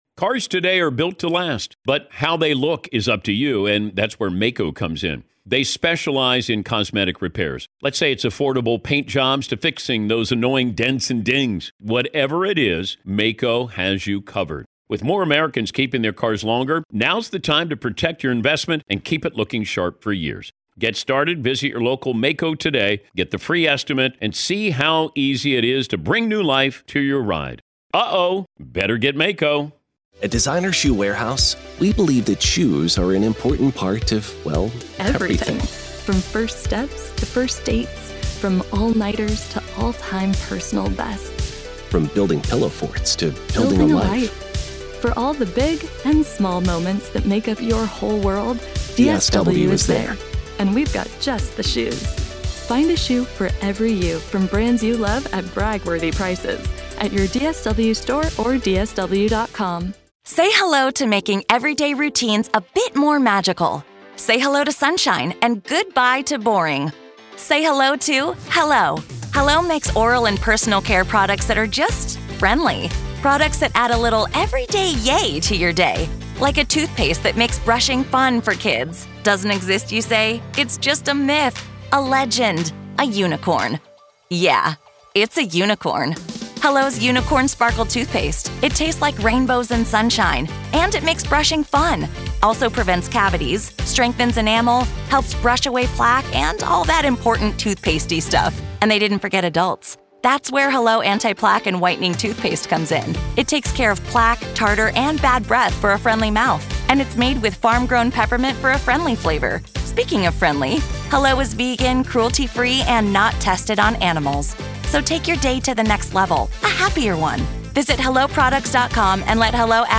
True Crime Today | Daily True Crime News & Interviews / Are The Feds Closing In On Diddy?